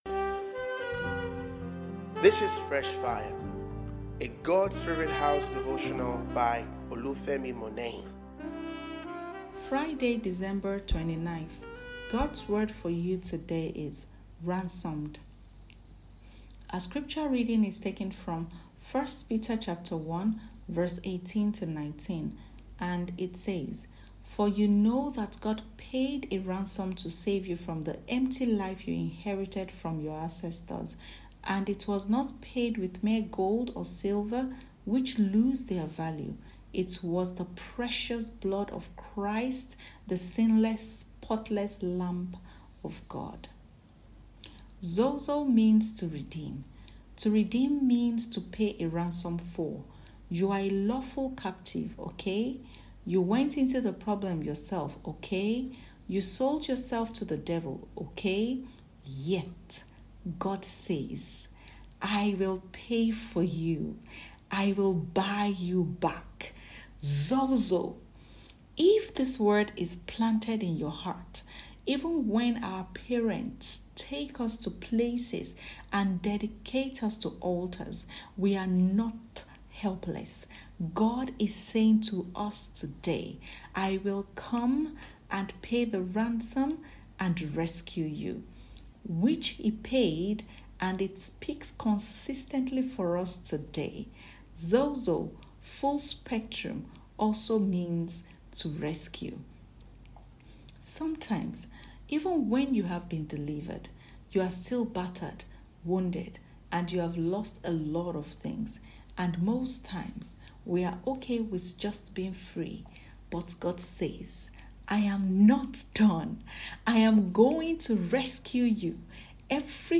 » Ransomed Fresh Fire Devotional